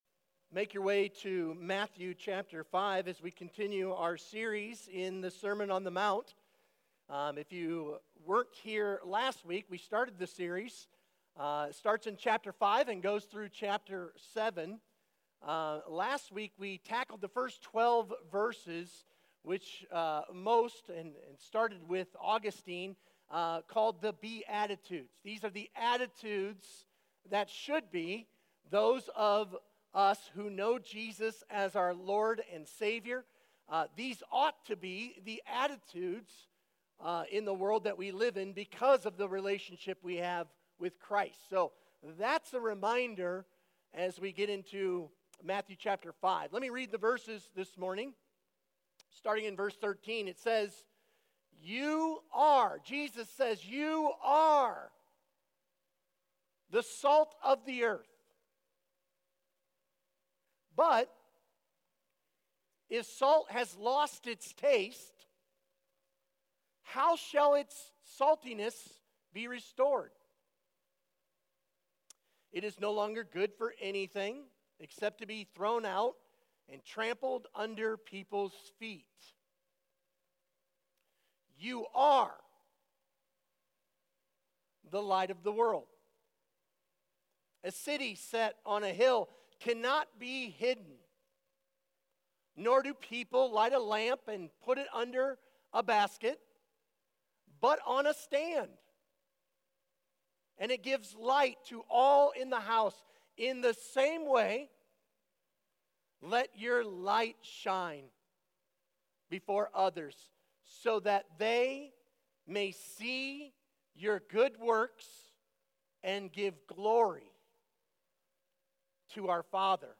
Sermon Questions Read Matthew 5:13-16.